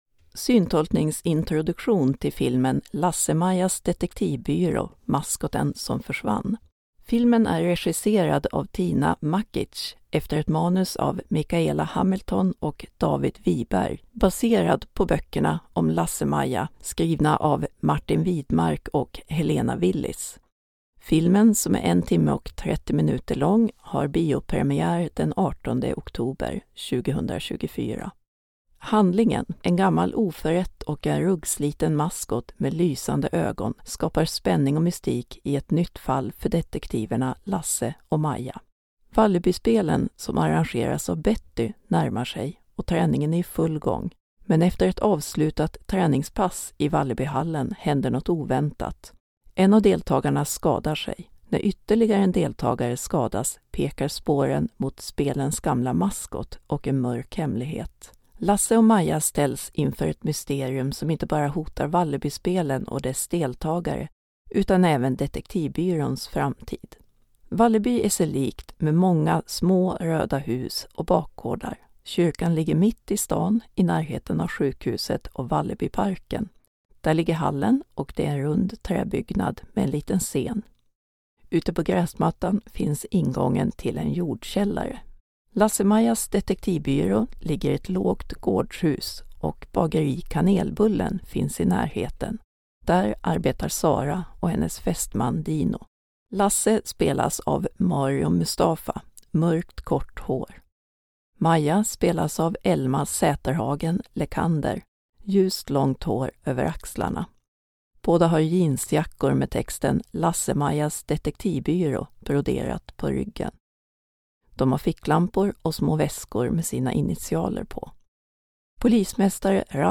LasseMaja_trailer-file.mp3